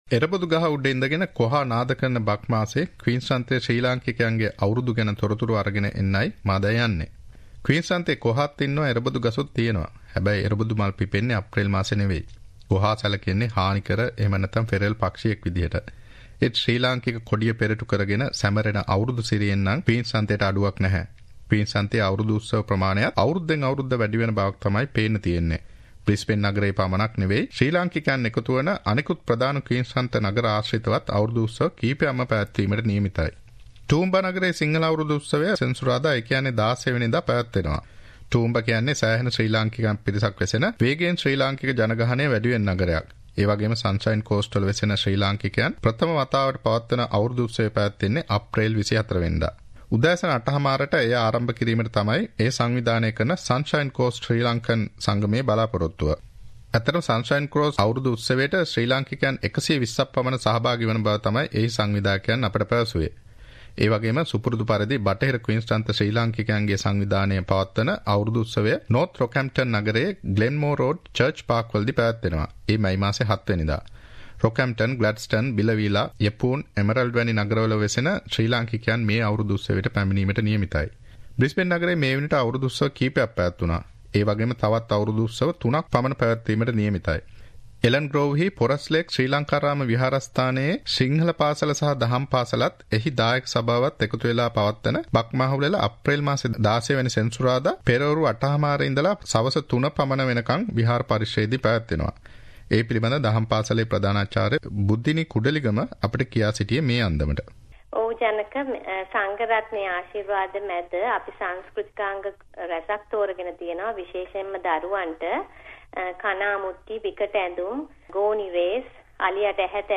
A report on Sinhala New Year celebrations in QLD